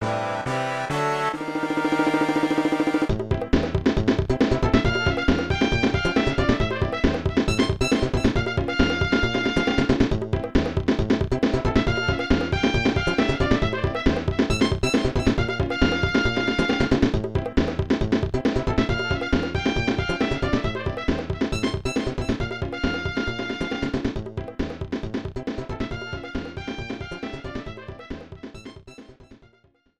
30 seconds with fadeout